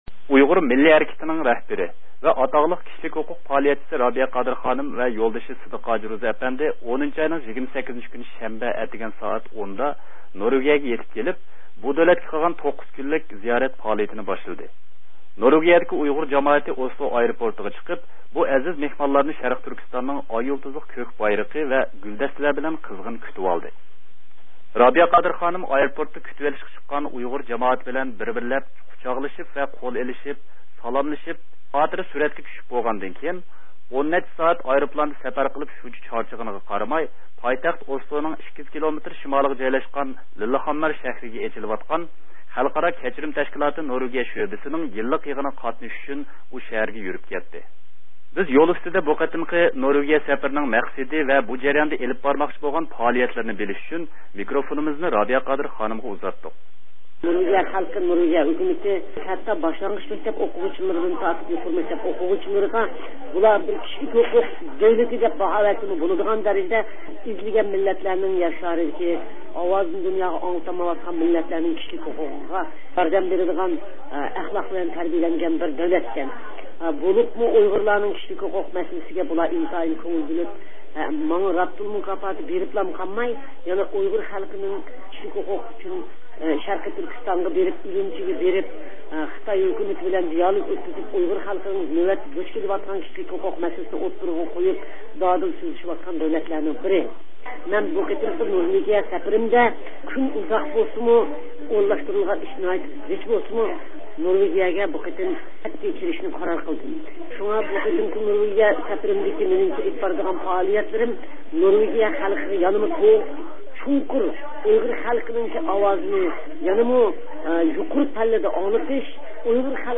زىيارىتىنى قوبۇل قىلىپ، نورۋېگىيىدىكى پائالىيەتلىرى ھەققىدە توختالدى.